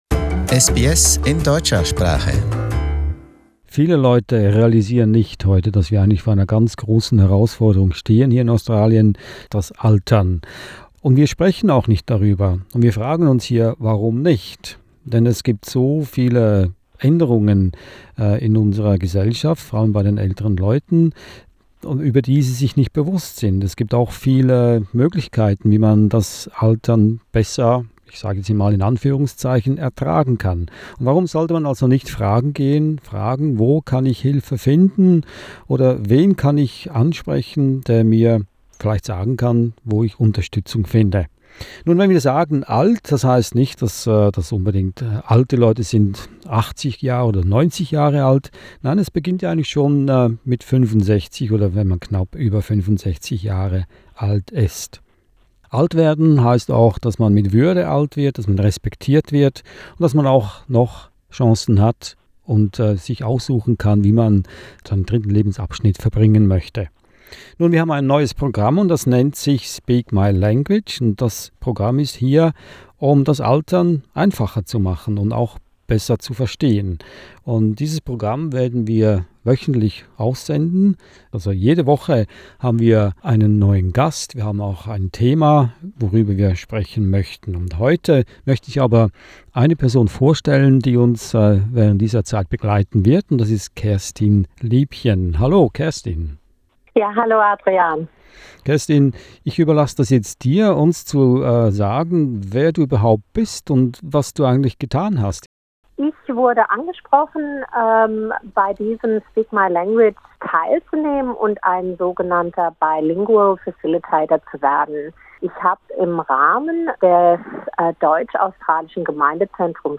has conversations with aged care experts in German language about ageing well in Australia.